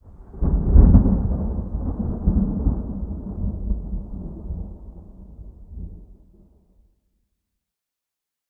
thunderfar_21.ogg